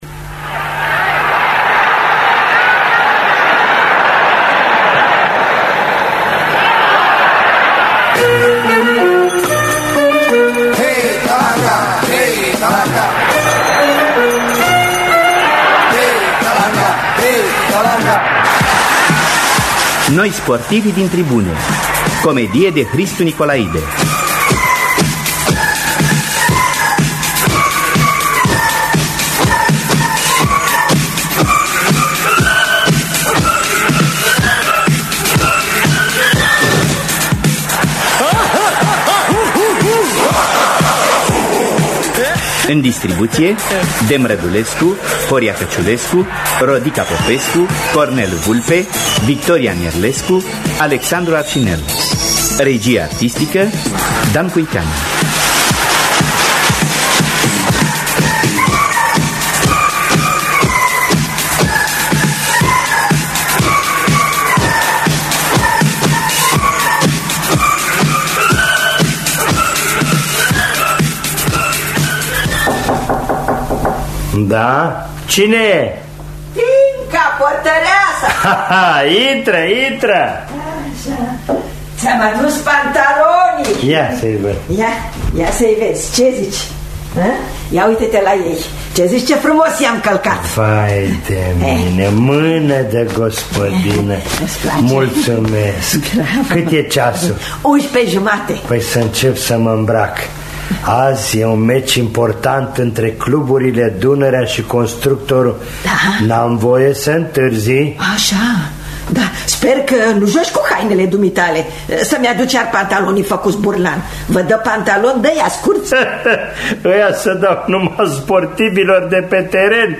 Noi, sportivii din tribune! de Hristu Nicolaide – Teatru Radiofonic Online